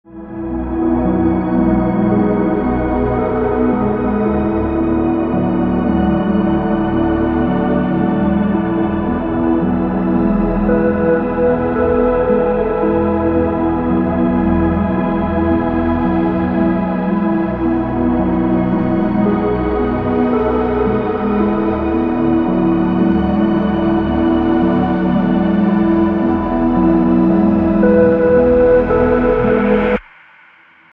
электроника